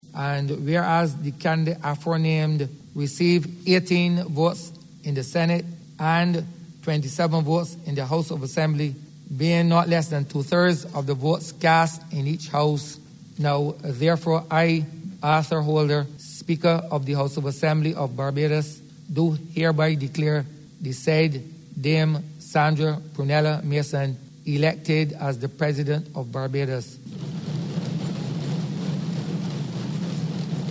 The vote went ahead and the Speaker of the House announced the result.